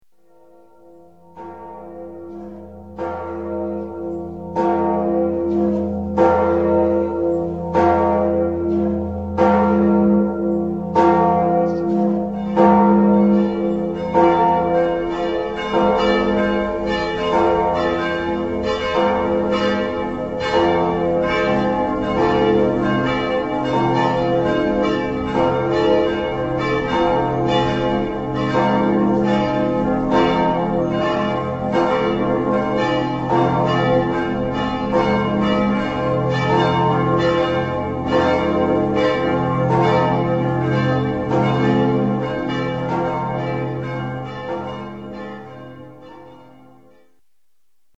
Glockenklang aus 8 europäischen Kirchen
Laudes – Glocken (Kathedrale von Szeged, Ungarn)
2_laudes_glocken.mp3